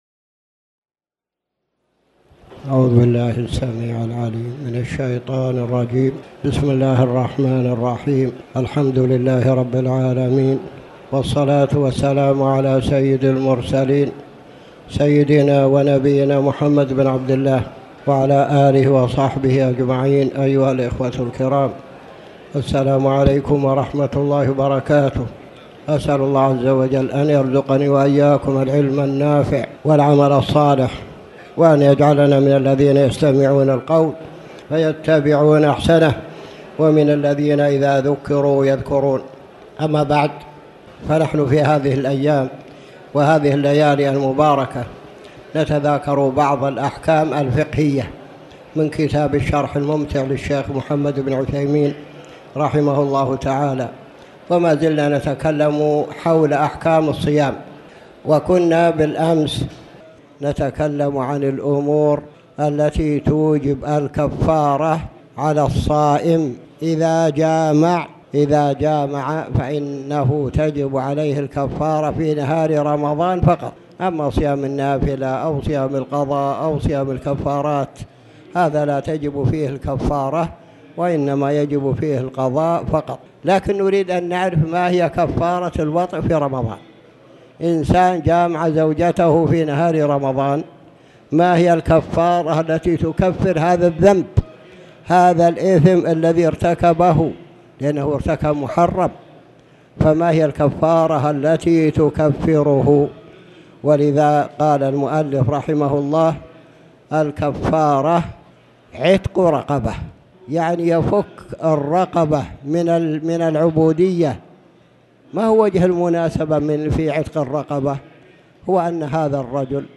تاريخ النشر ٢٧ جمادى الأولى ١٤٣٩ هـ المكان: المسجد الحرام الشيخ